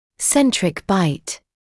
[‘sentrɪk baɪt][‘сэнтрик байт]центральная окклюзия